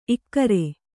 ♪ ikkare